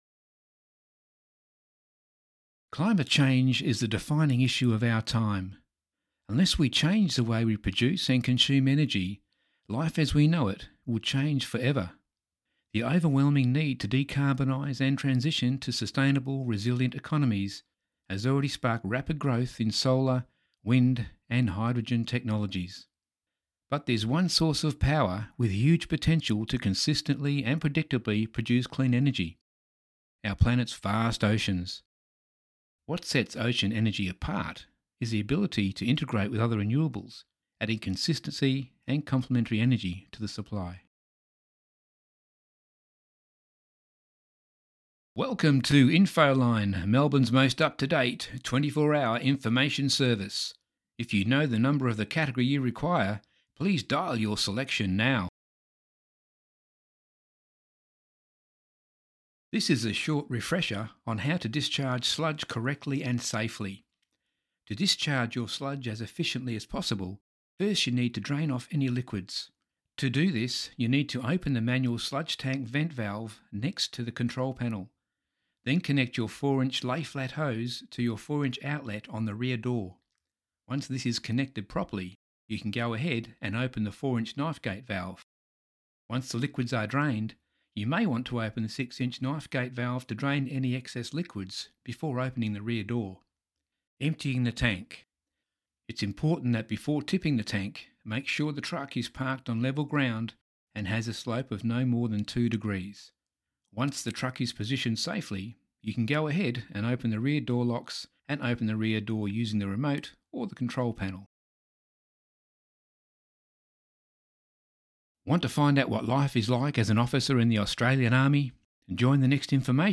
Browse professional voiceover demos.
Authorative yet relaxed with a lovely natural timbre
English (New Zealand) Adult (30-50) | Older Sound (50+)